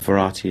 First, opera singer Simon Keenlyside, smoothing variety from var[ɑjə]ty to var[ɑː]ty, which could rhyme with party: